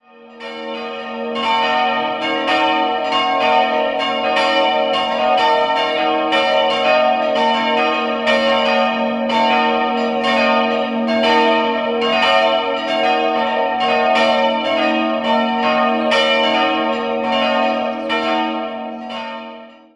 Steinsberg, Pfarrkirche St. Josef Der Ort Steinsberg liegt zwischen Regensburg und Burglengenfeld und gehört politisch zur Marktgemeinde Regenstauf. Die Pfarrkirche St. Josef wurde in den Jahren 1929/30 in gotisierenden Formen durch den Architekten Karl Schmid sen. errichtet. 4-stimmiges Geläute: b'-des''-es''-b'' Die drei großen Glocken wurden 1956 von Georg Hofweber in Regensburg gegossen. Die kleine Glocke stammt aus dem Jahr 1801.